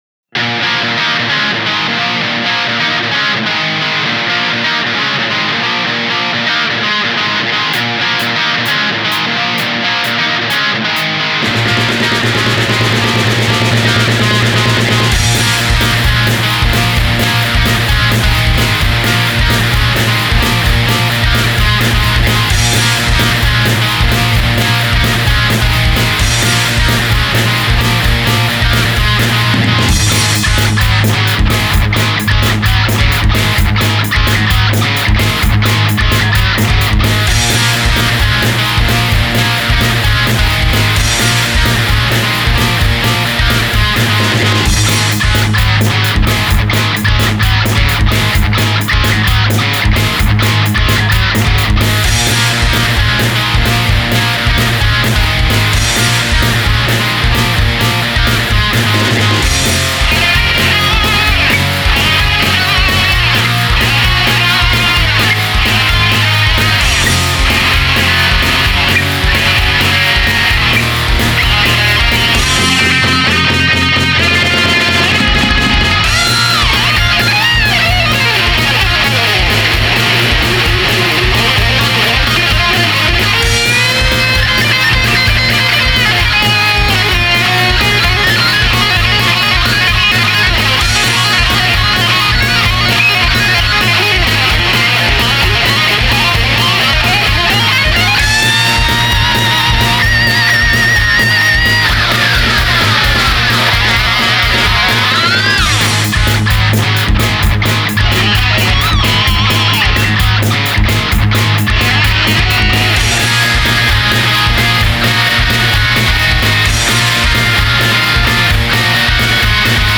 スピード感のある一曲。